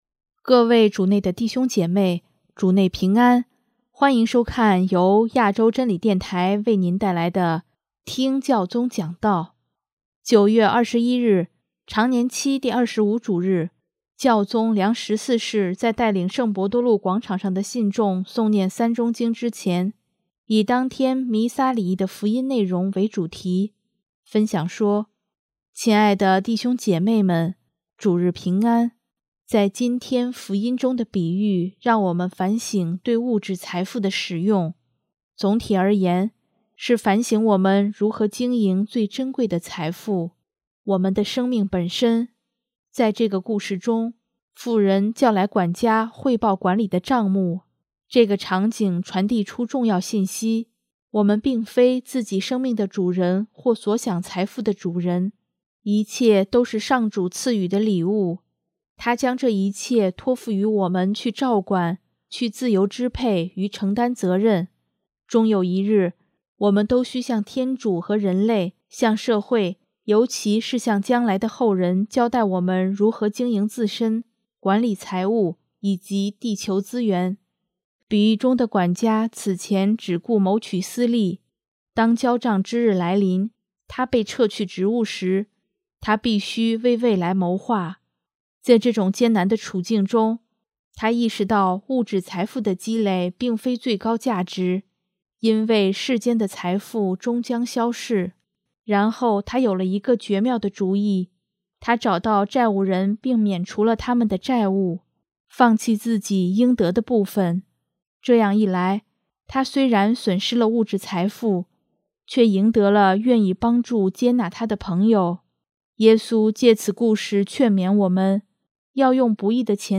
【听教宗讲道】|我们如何经营最珍贵的财富